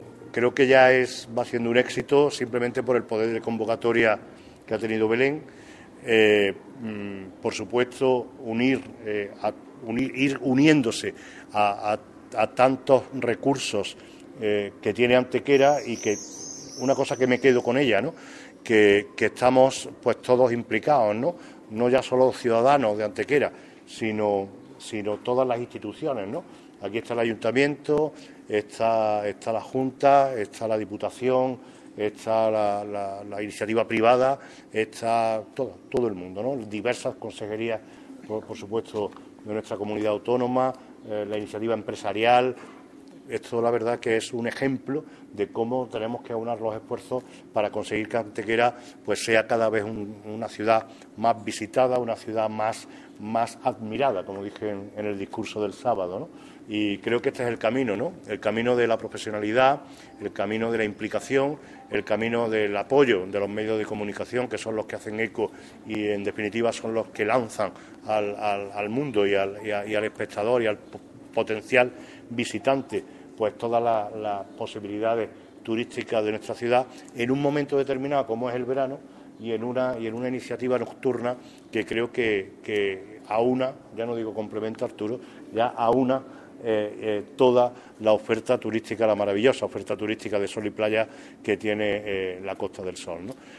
Cortes de voz M. Barón 733.07 kb Formato: mp3